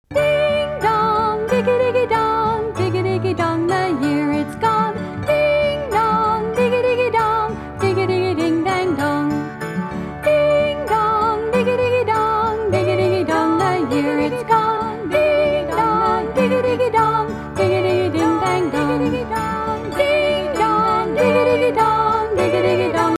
Traditional Lyrics
Sing as a round to celebrate New Year's Day: